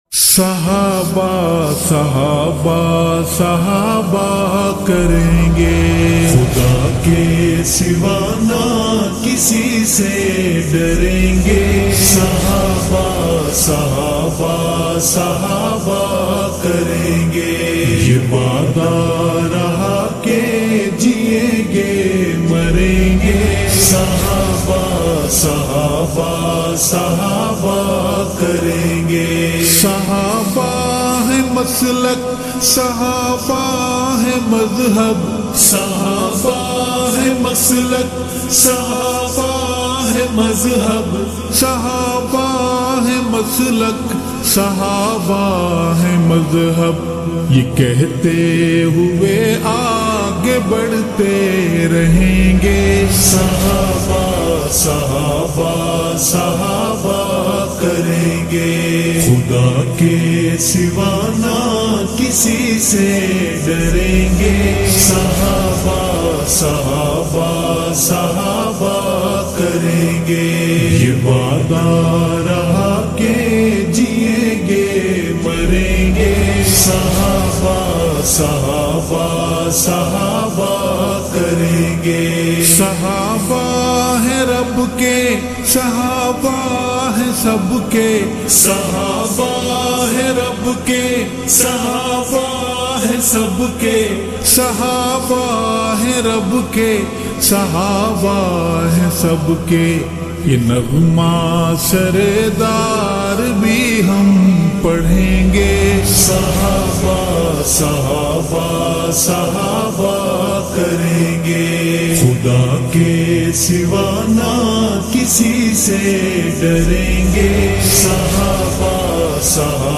Slowed Reverb Naat